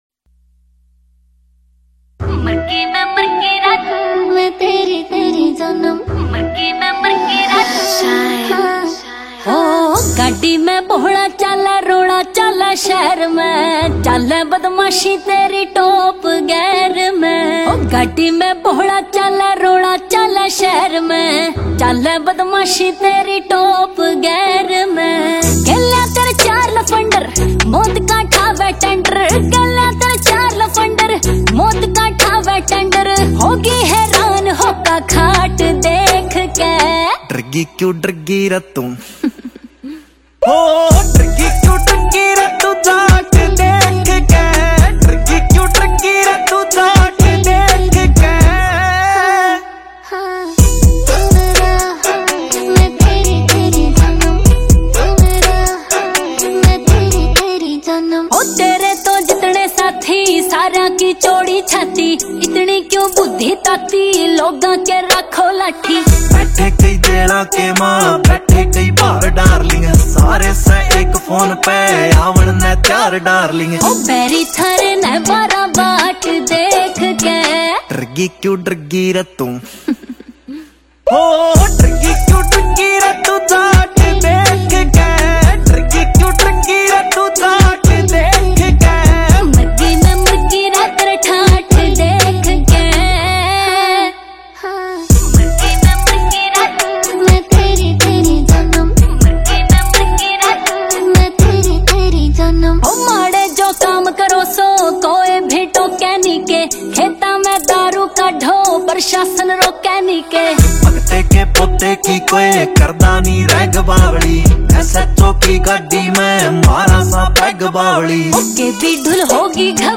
The lyrics show gangster type confidence.